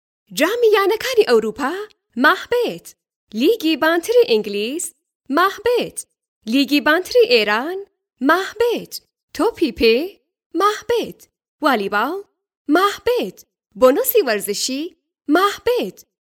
Female
Adult
Commercial